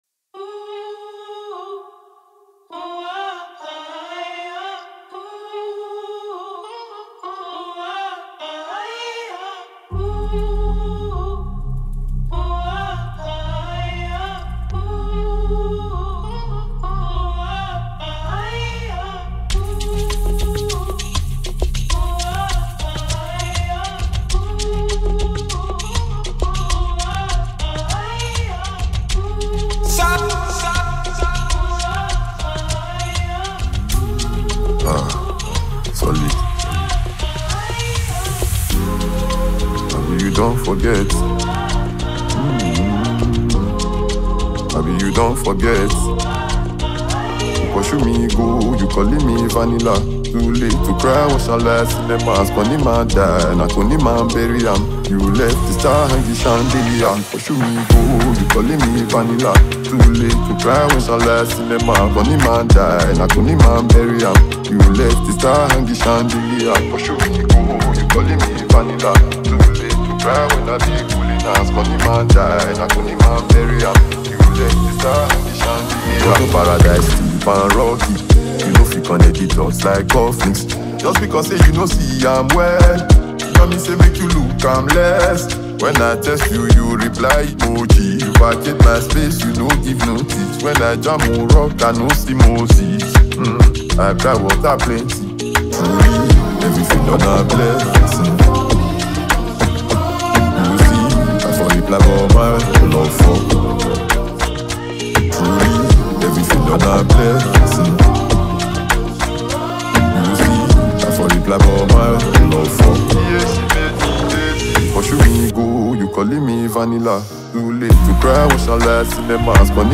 ” an interesting melody.